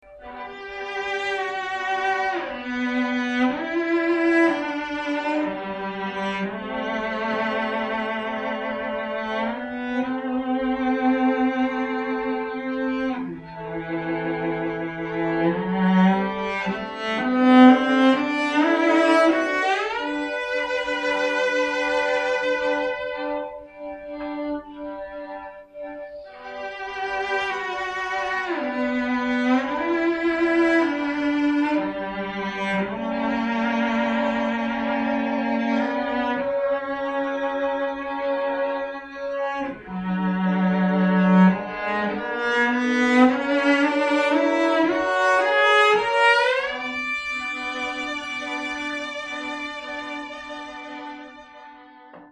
o  There is very little difference in volume between a Trio and a Quartet, but they have a different sound.
Swan trio short.mp3